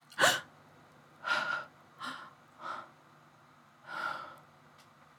gasp1.wav